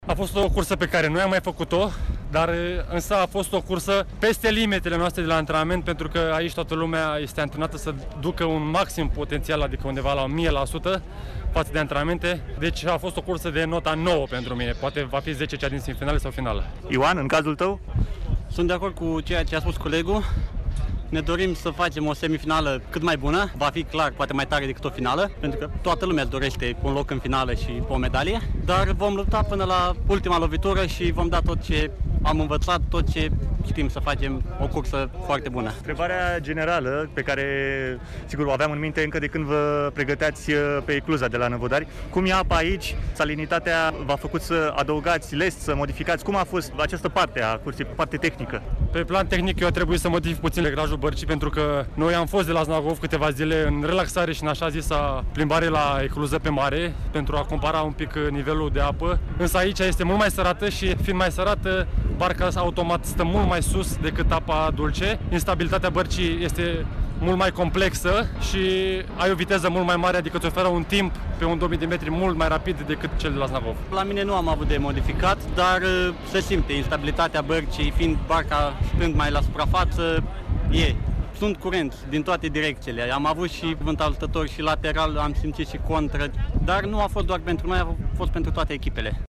și-au exprimat impresiile ”la cald”, după cursa de azi, într-un dialog